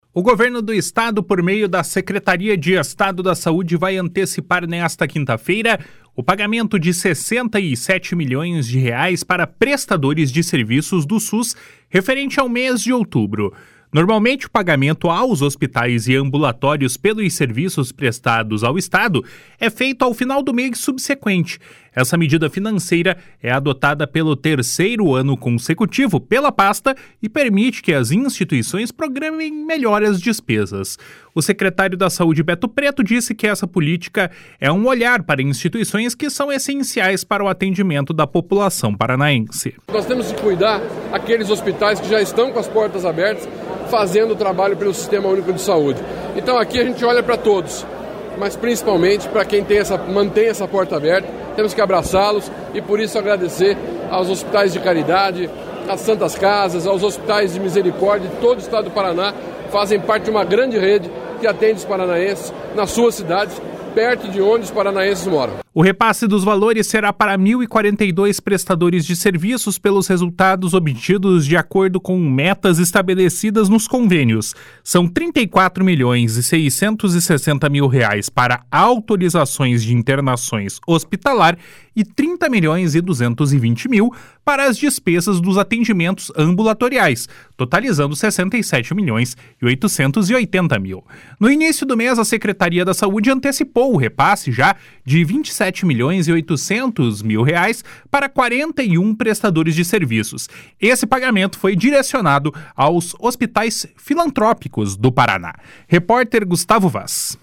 O secretário da Saúde, Beto Preto, disse que essa política é olhar para instituições que são essenciais para o atendimento da população paranaense.